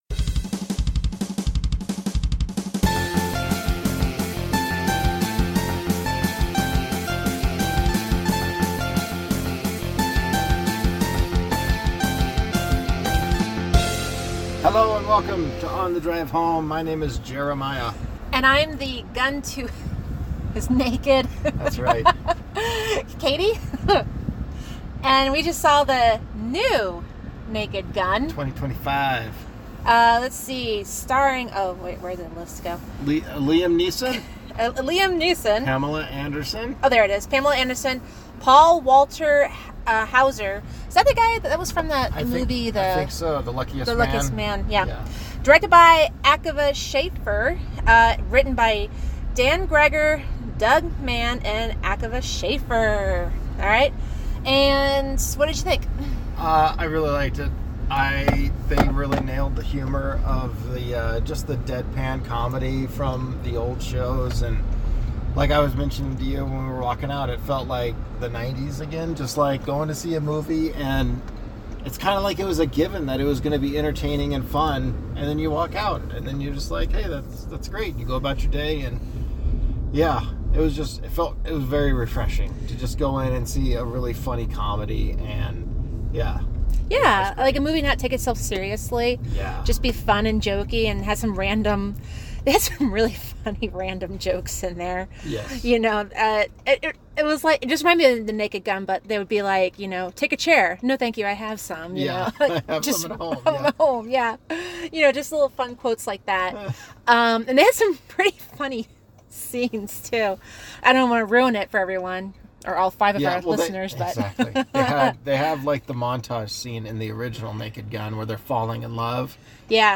Movie reviews